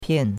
pian4.mp3